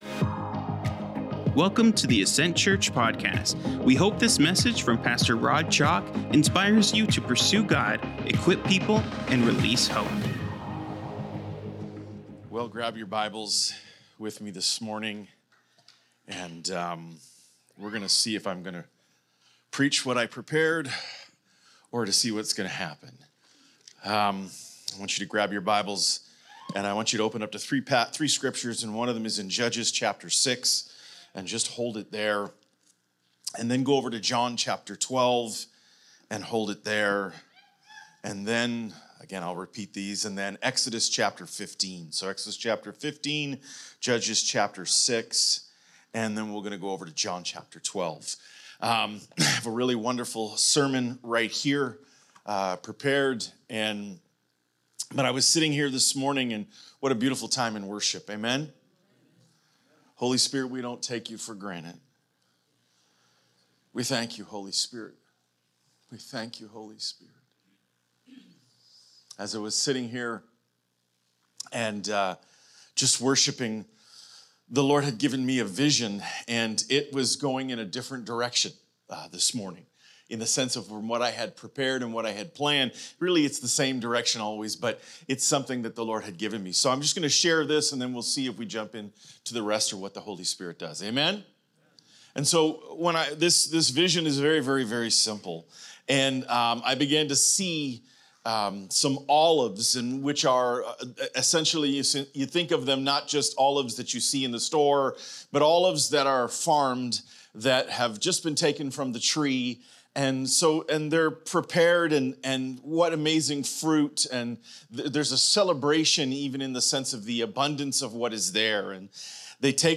Sermons | Ascent Church